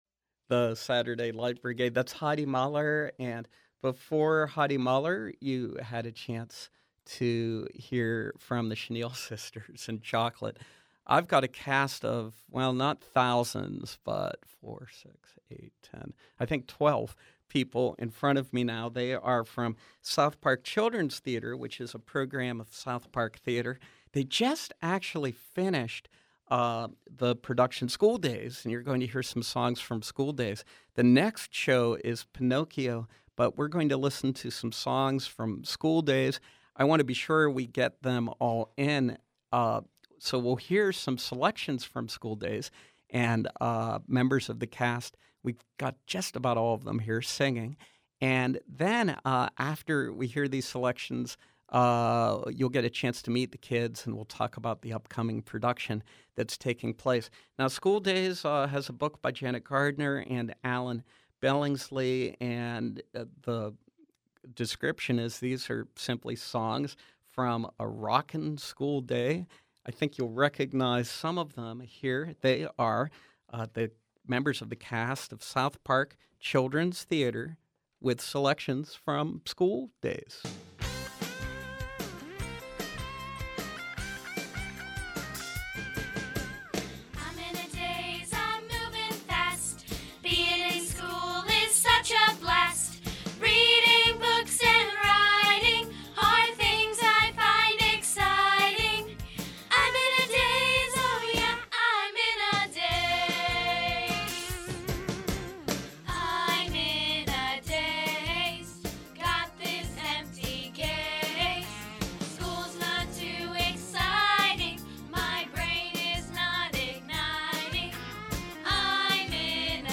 Joining us is the cast of South Park Children’s Theatre, performing selections from their latest production.